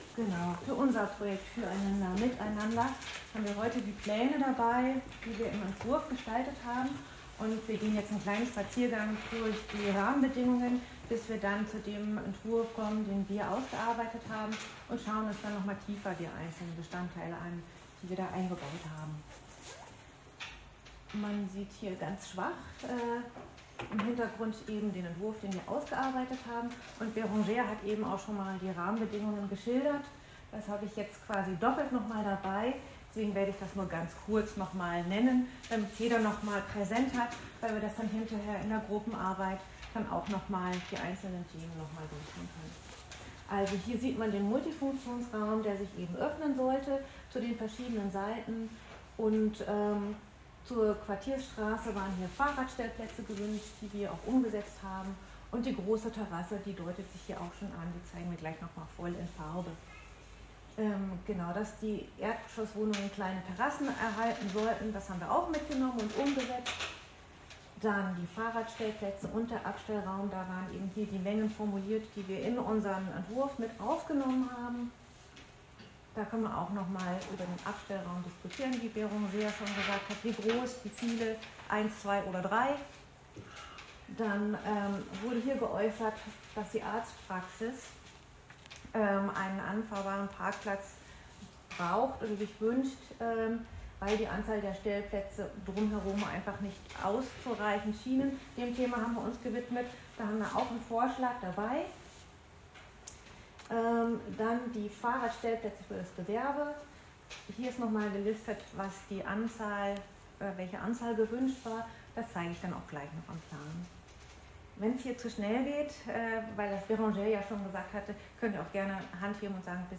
Workshop vom 10.10.25